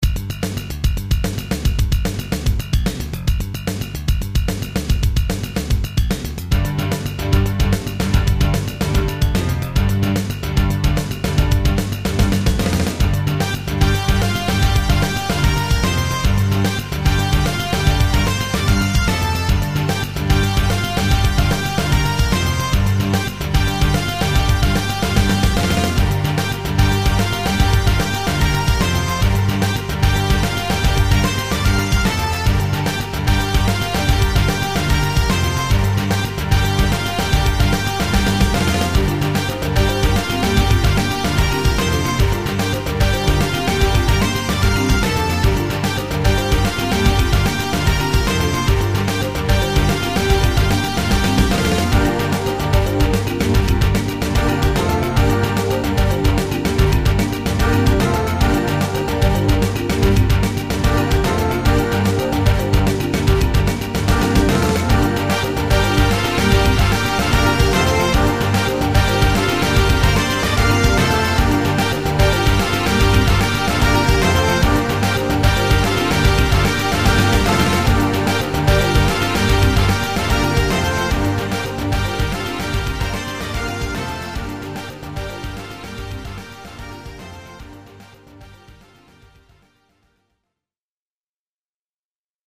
ちなみに五音階のメロディです。出来上がったのはファンクっつーかロックっつーか、そっち方面の音楽っぽくなったかな？
トラックはまず、ベースとドラムのリズム隊のトラックが入る。
さらに管弦楽器を細かく刻んで弾いているトラックを挿入。
マリンバによるアルペジオ的なループトラックをぶち込む。